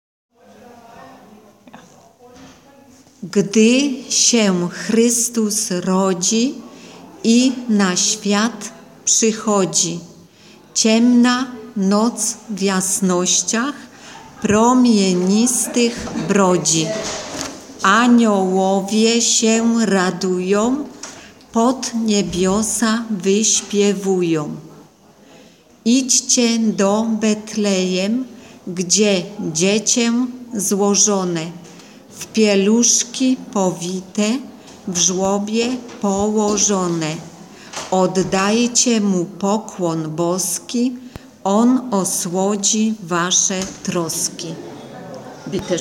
Verstärkt von ca. 15 Projektsängern wurden Lieder in polnischer, kroatischer und aramäischer Sprache gesungen.
Hier stehen Übungsstücke zum Hören und Download bereit!